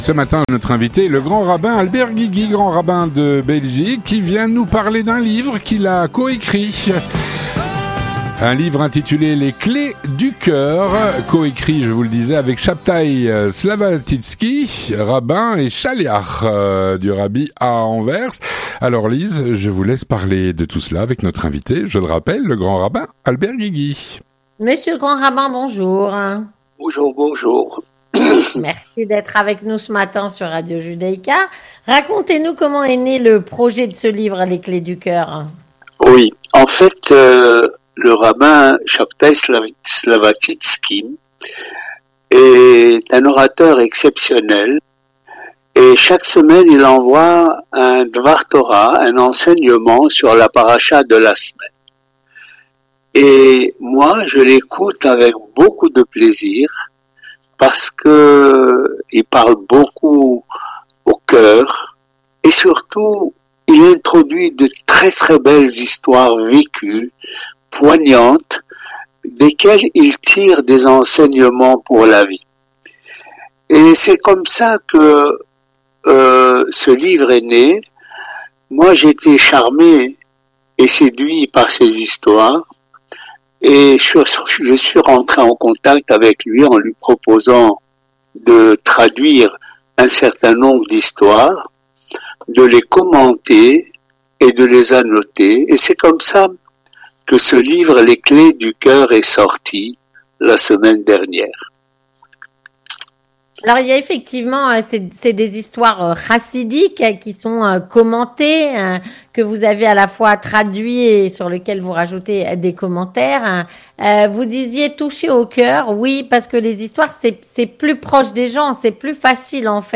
Le Grand Rabbin de Bruxelles, Albert Guigui, est notre invité et nous parle de ce livre.